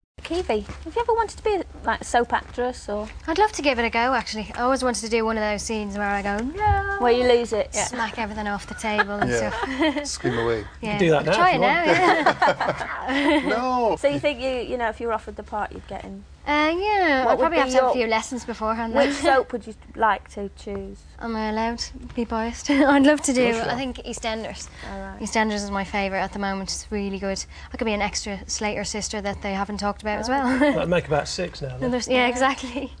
Keavy appeared as a guest presenter on ITV1`s show This Morning.
Some clips have been edited to remove dead air. All crackling/rustling is from the microphones rubbing on clothing.